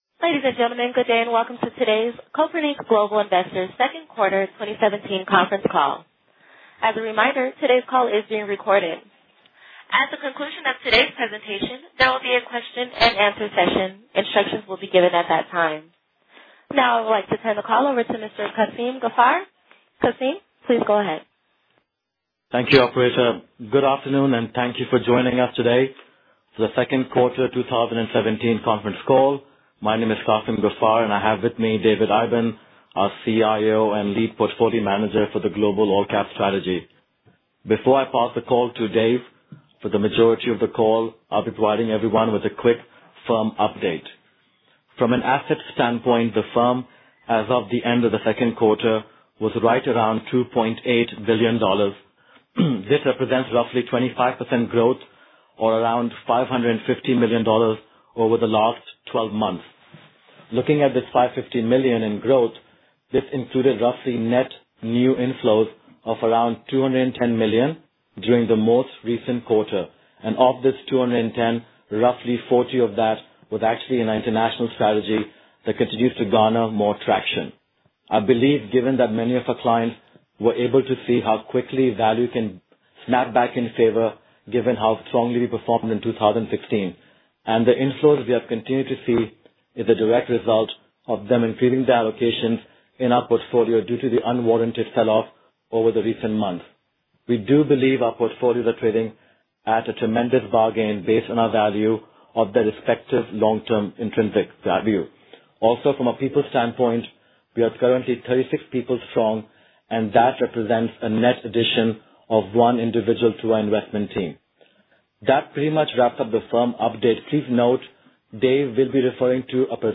Q2 2017 Conference Call - Kopernik Global Investors